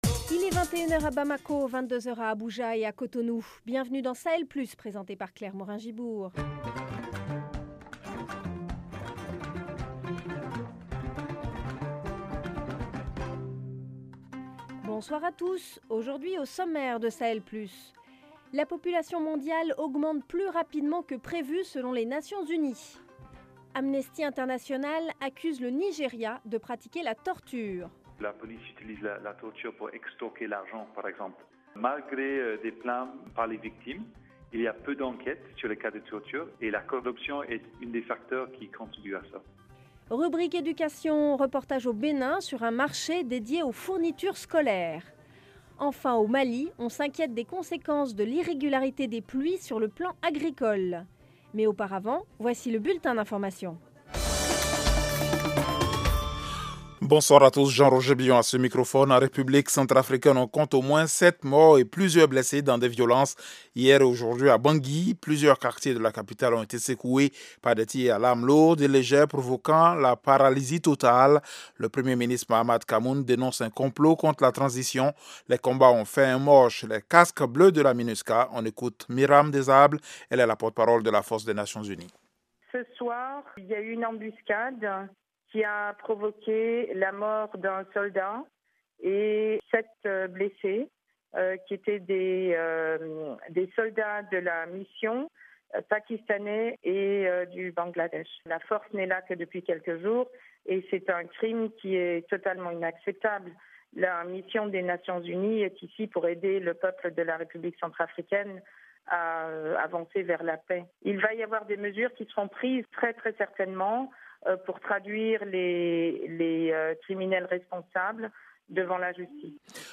Amnesty International accuse le Nigéria de pratiquer la torture. Reportage auBenin sur un marché dédié aux fournitures scolaires. Au Mali, on s’inquiète des conséquences de l’irrégularité des pluies pour l'agriculture.